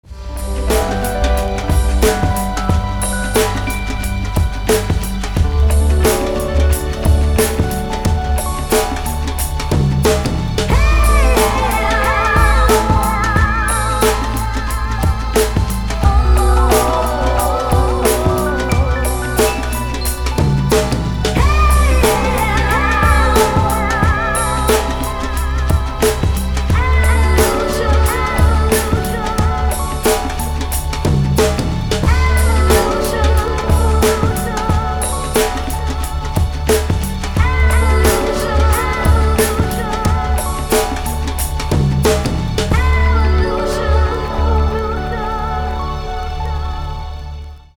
• Качество: 320, Stereo
Electronica
house
медлячок
Lounge
хаус, электроника, лаунж